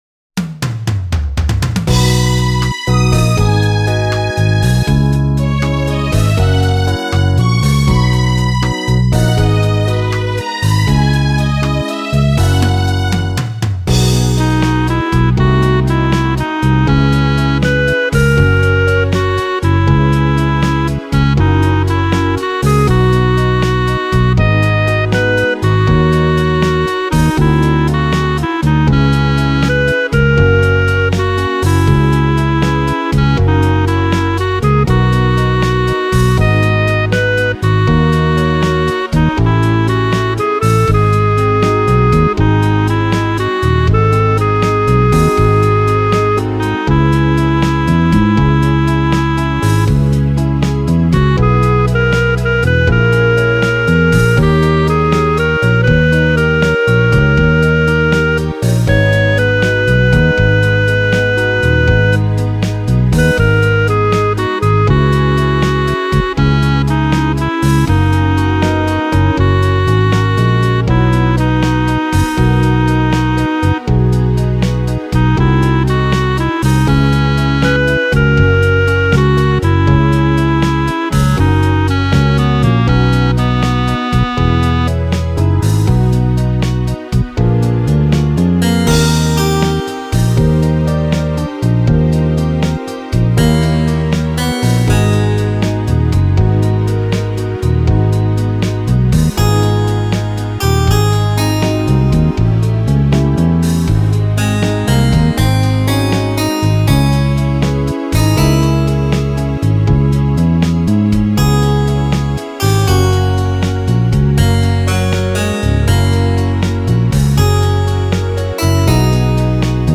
2977   03:55:00   Faixa: 4    Clássica